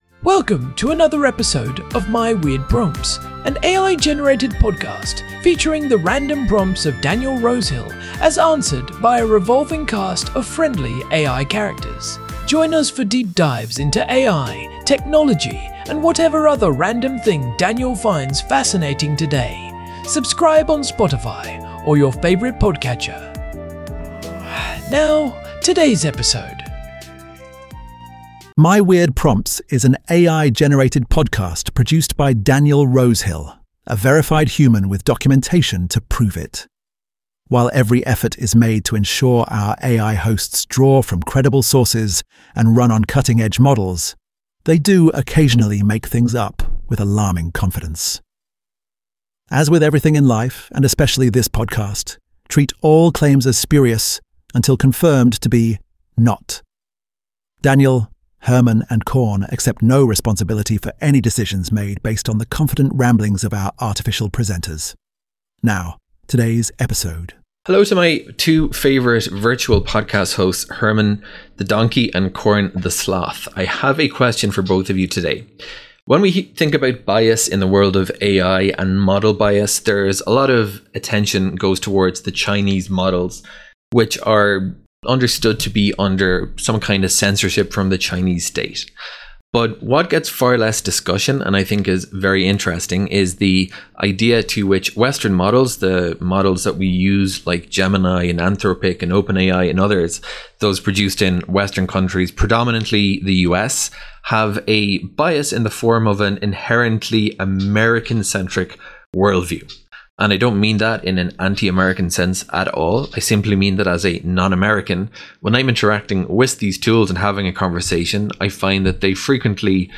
AI-Generated Content: This podcast is created using AI personas. Please verify any important information independently.